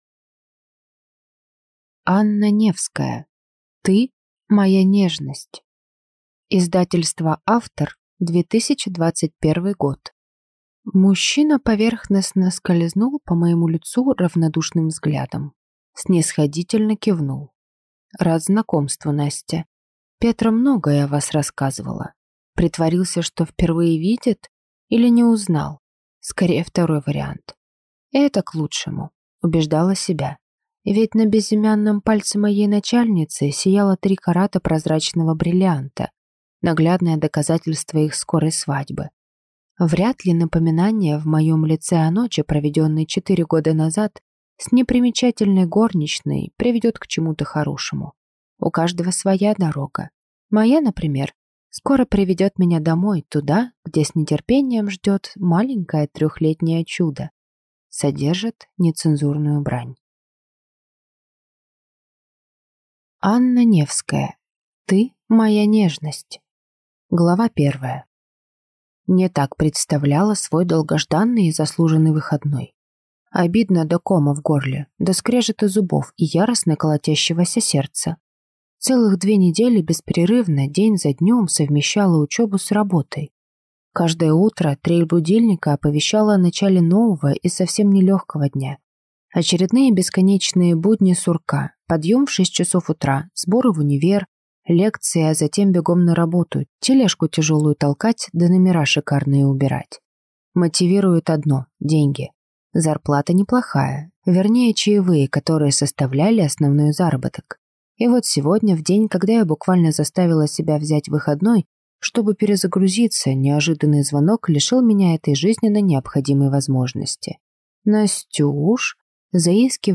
Аудиокнига Ты – моя нежность | Библиотека аудиокниг